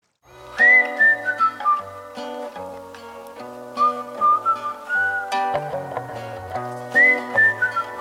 • Качество: 321, Stereo